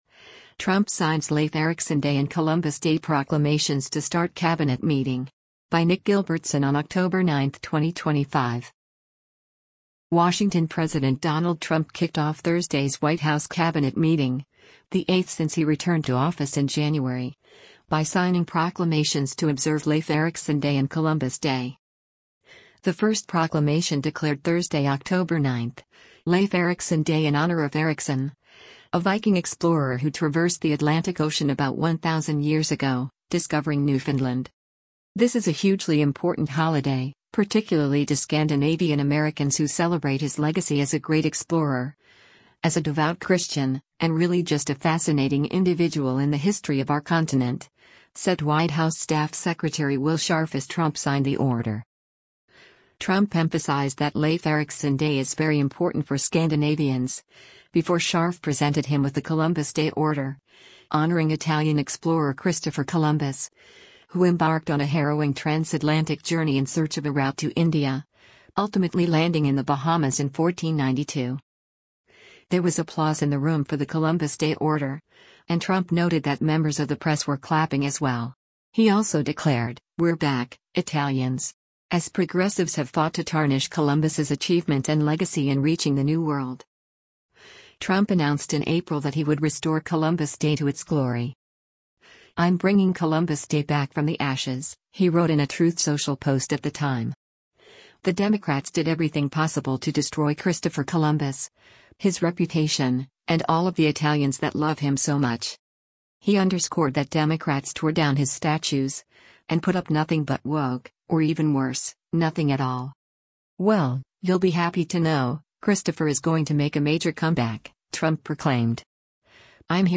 WASHINGTON–President Donald Trump kicked off Thursday’s White House Cabinet Meeting, the eighth since he returned to office in January, by signing proclamations to observe Leif Erickson Day and Columbus Day.
There was applause in the room for the Columbus Day order, and Trump noted that members of the press were clapping as well.